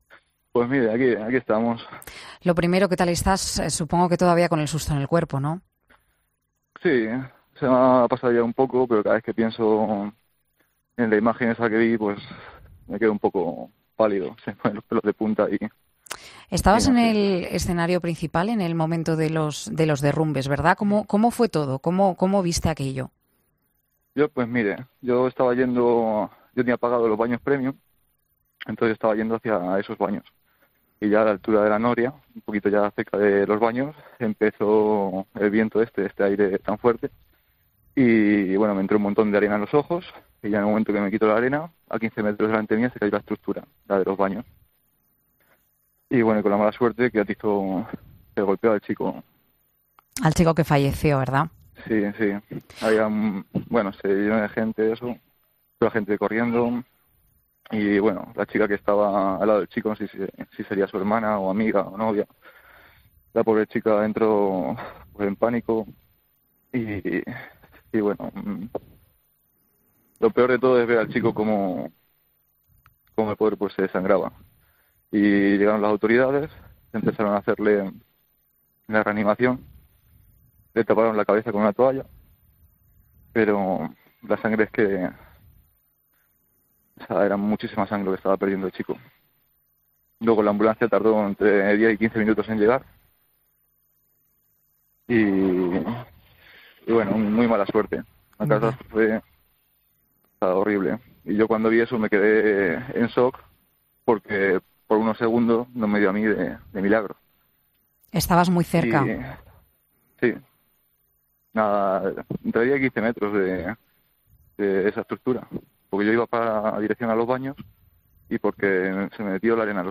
Dos días después del trágico suceso, un testigo relata en COPE lo sucedido en el Festival